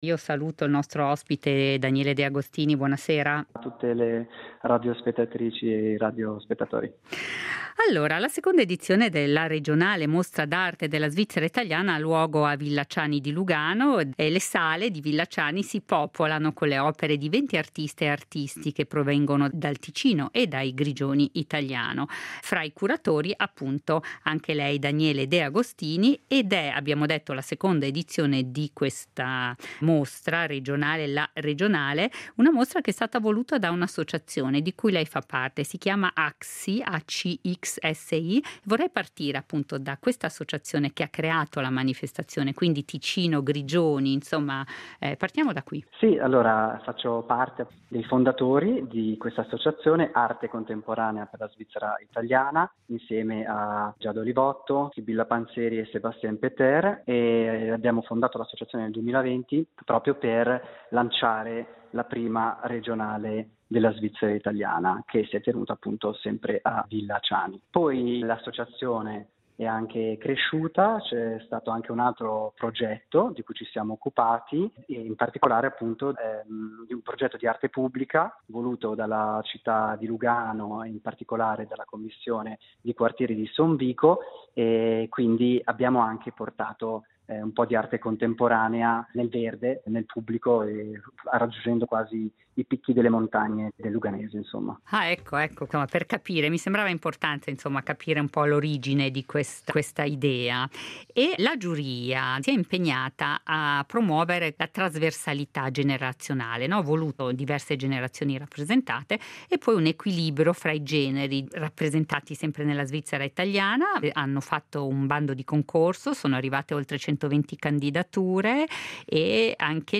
Fra i curatori de “La Regionale”